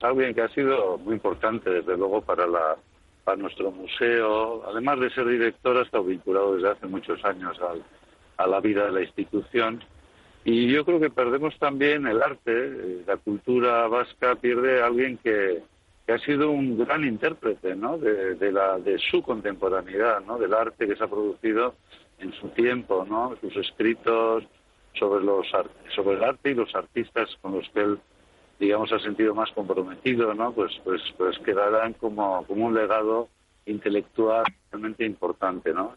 Tras 15 años al frente de la pinacoteca Viar pasó las riendas del museo a Miguel Zugaza, quien le recordaba en Onda Vasca como un gran amigo comprometido al máximo con la cultura vasca